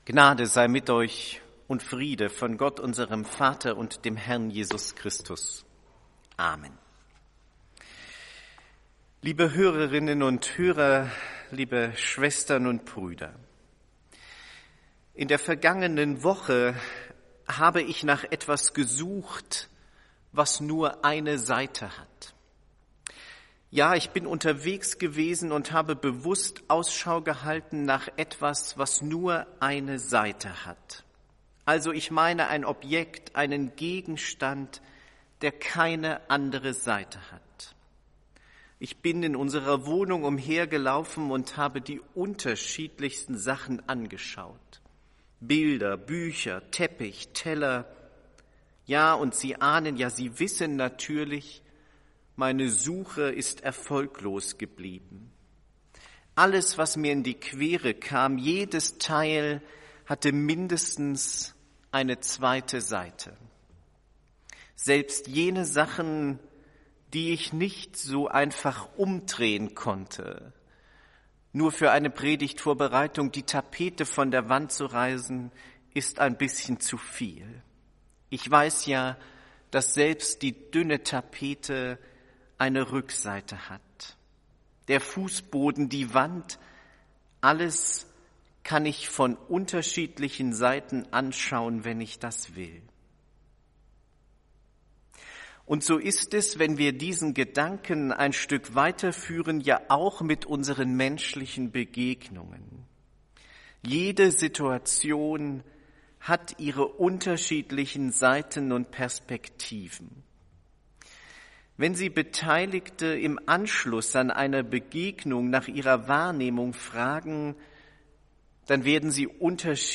Predigt des Gottesdienstes aus der Zionskirche vom Sonntag, 21.03.2021
Wir haben uns daher in Absprache mit der Zionskirche entschlossen, die Predigten zum Nachhören anzubieten.